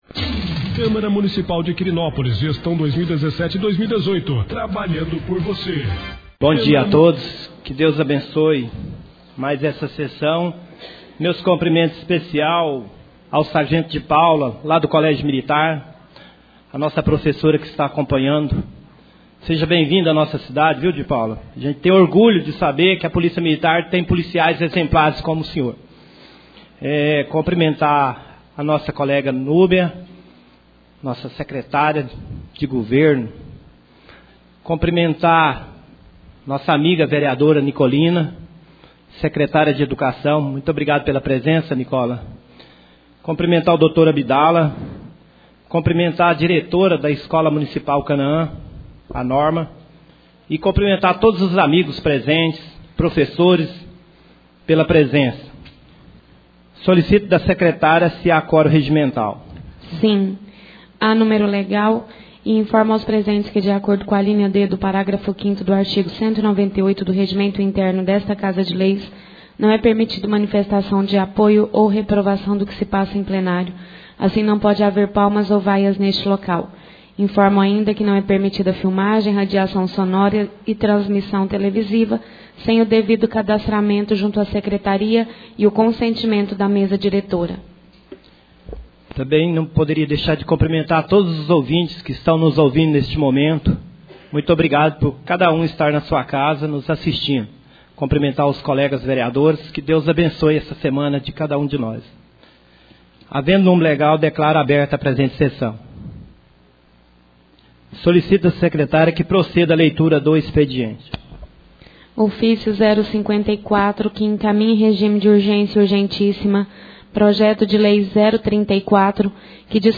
1° Sessão Ordinária do Mês de Outubro 2017.